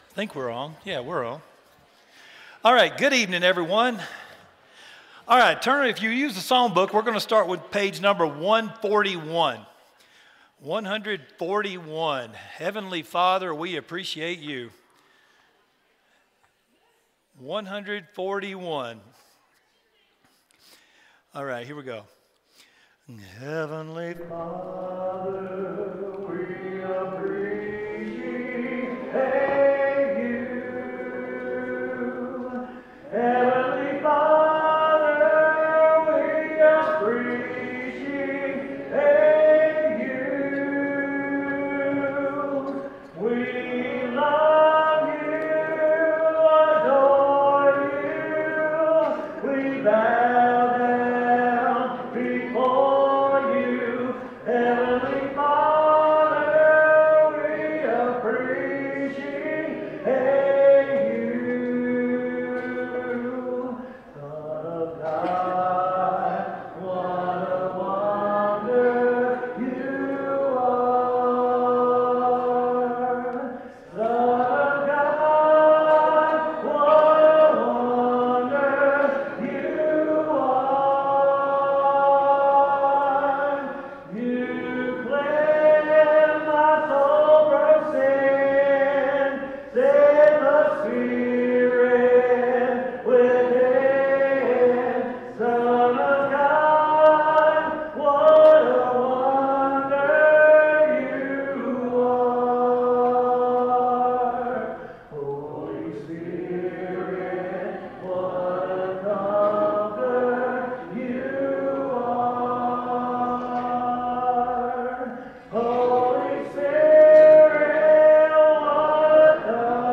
Deuteronomy 12:32, English Standard Version Series: Sunday PM Service